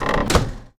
better chest sounds
default_chest_close.ogg